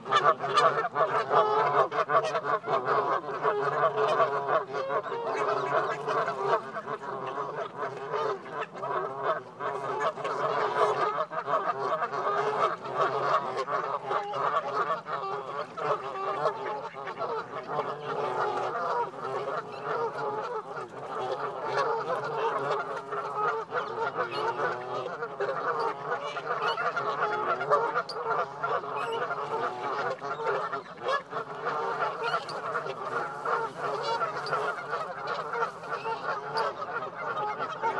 Commotion on the duck pond